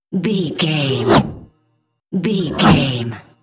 Whoosh fast creature
Sound Effects
futuristic
whoosh
sci fi